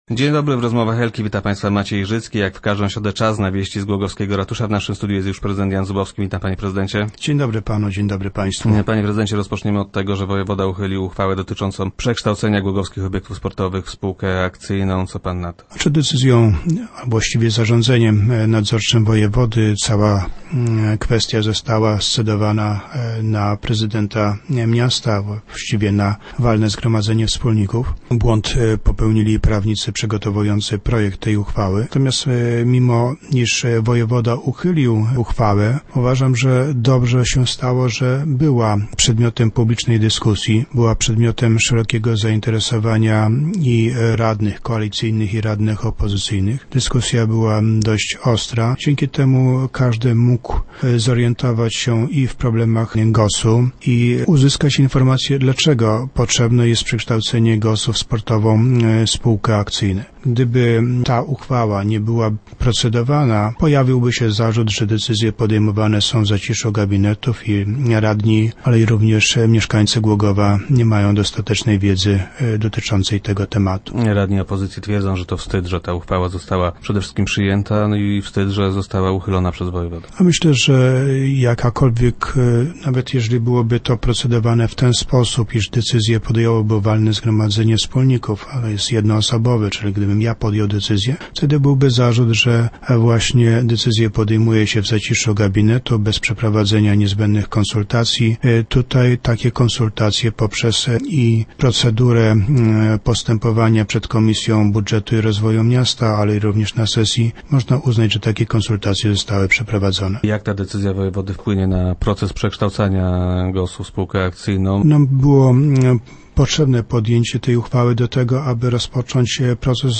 Prezydent Zubowski był gościem Rozmów Elki.